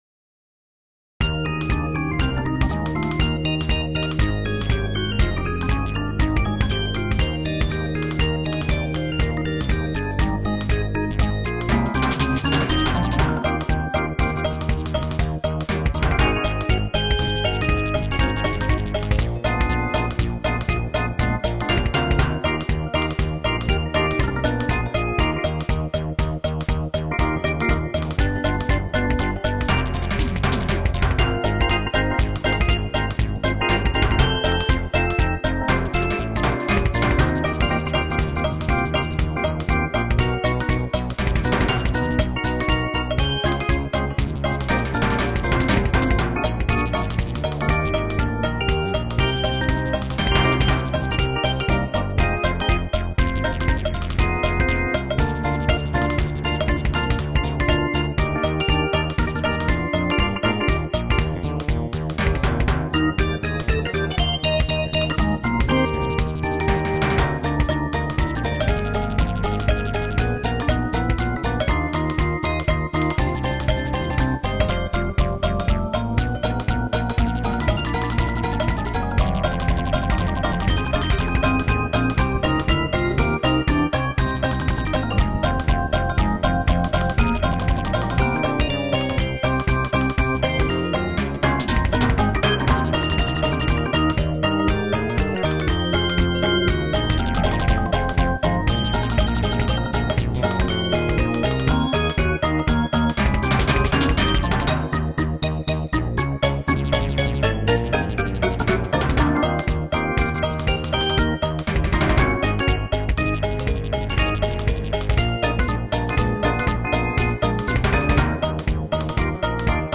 Jazzy